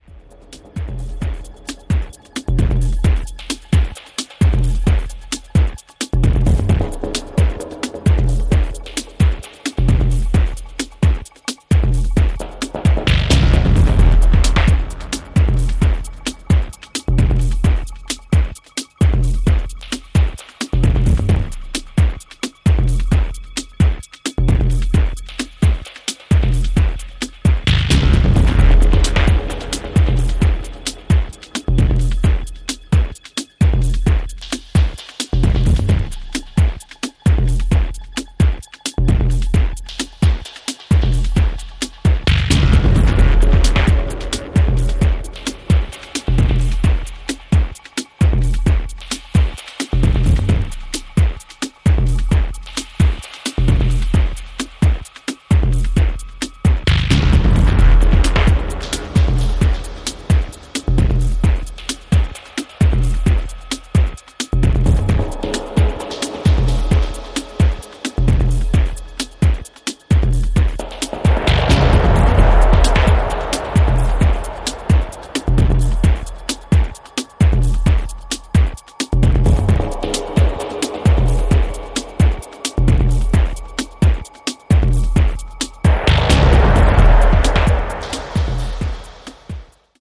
Ambient
Techno